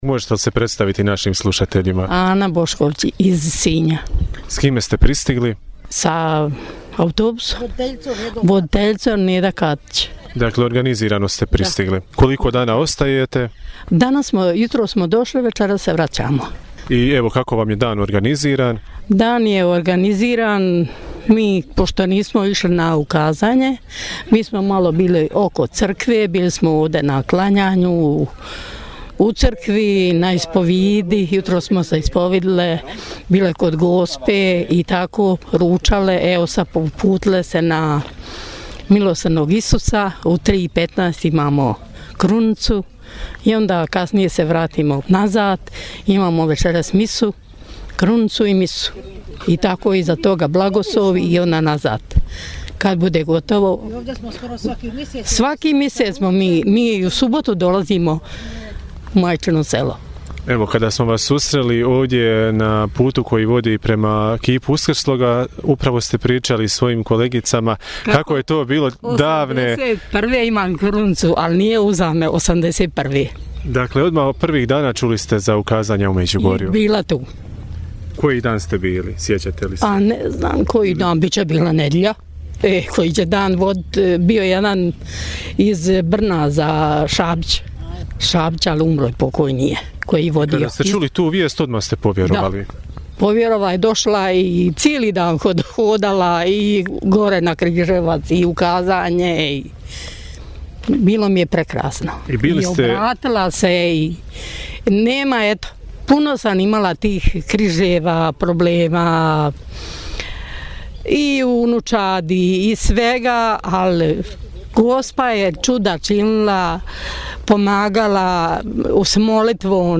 Tijekom proslave 42. obljetnice ukazanja Kraljice Mira u Međugorju, susreli smo mnoštvo hodočasnika koji su došli iz raznih dijelova svijeta.